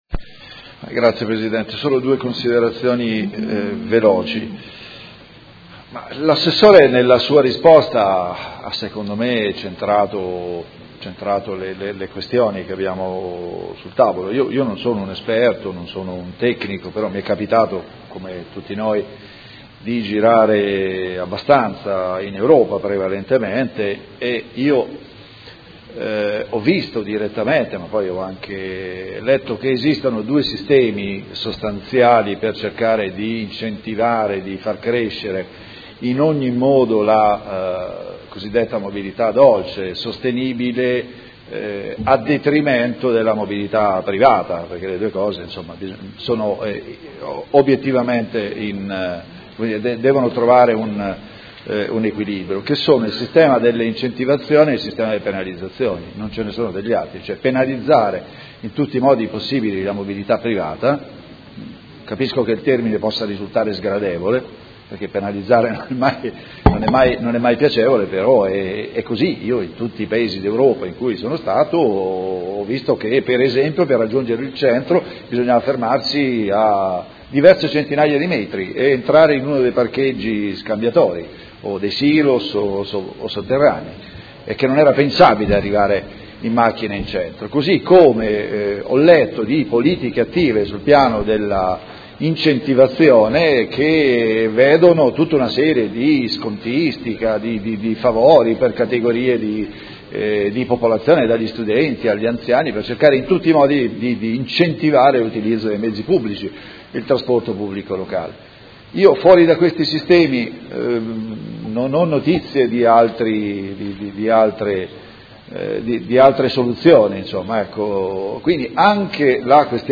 Seduta del 31/03/2016. Interrogazione del Gruppo Consiliare Forza Italia avente per oggetto: Restringimento della carreggiata di Via Pietro Giardini. Dibattito